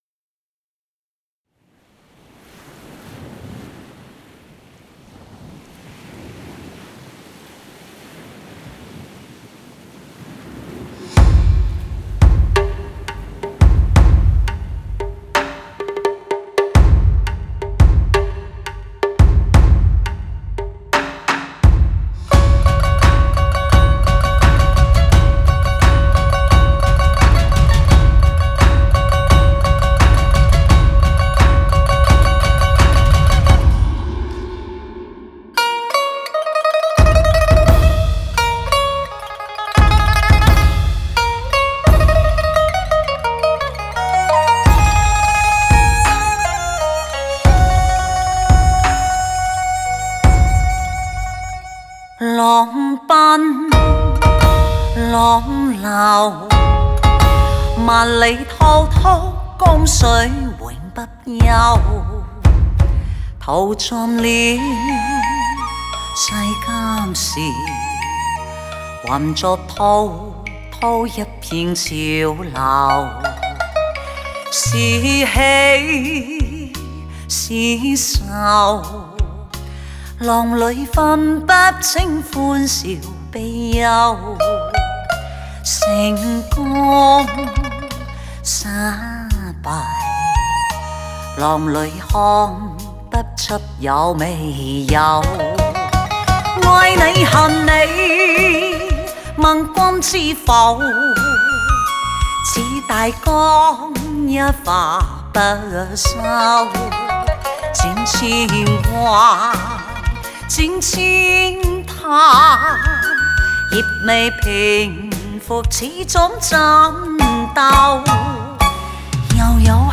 国语流行